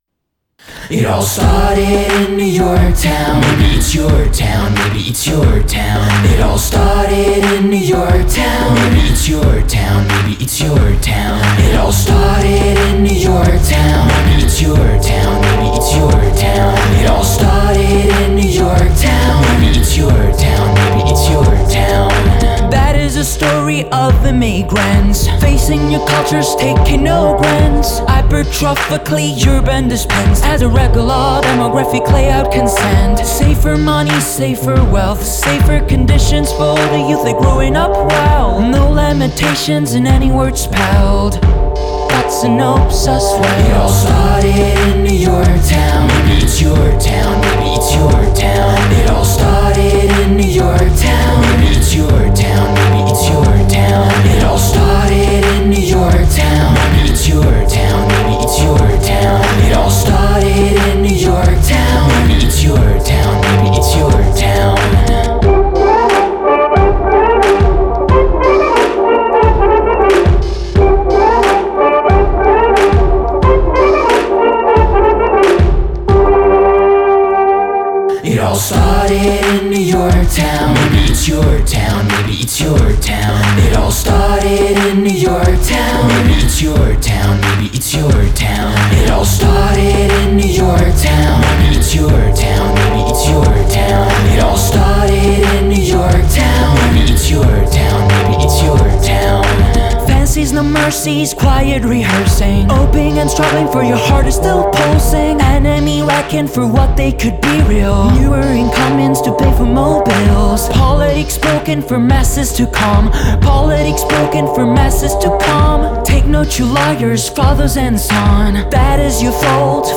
Now they've started a new project, more hip-hop oriented.
Add dark lyrics delivered with an apocalyptical strain and